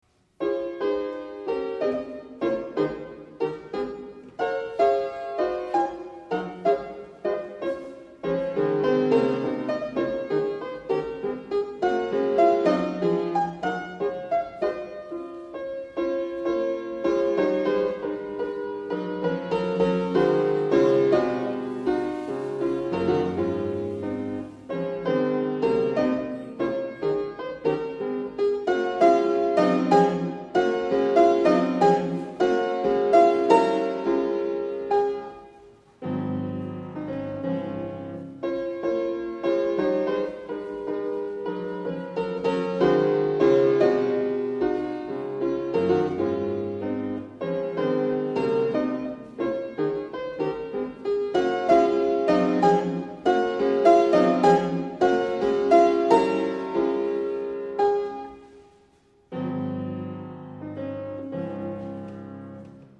Sonate cis-Moll op. 27
Allegretto